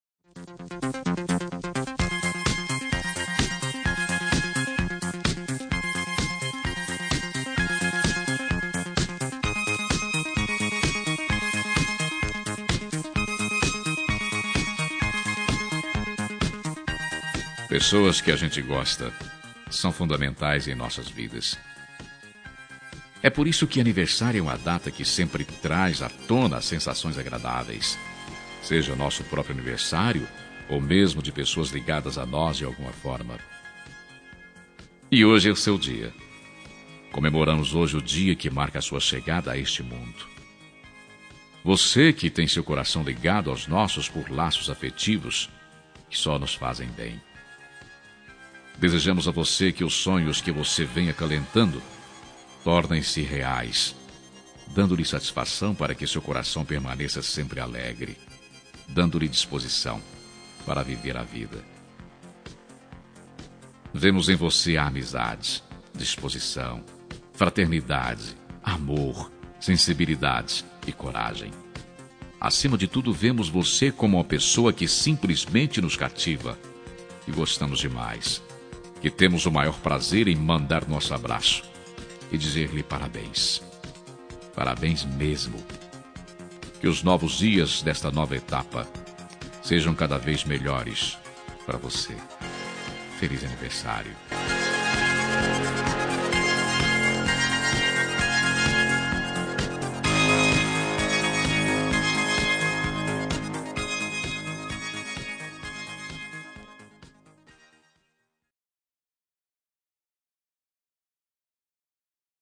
Telemensagem de Aniversário de Pessoa Especial – Voz Masculino – Cód: 1929
2N 40 Orquestrada.mp3